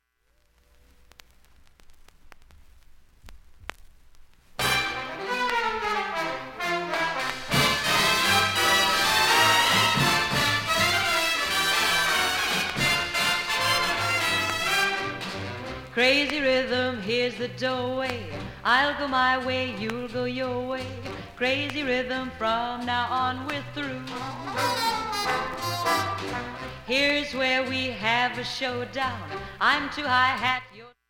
ほか単発のかすかなプツが３箇所
Stereo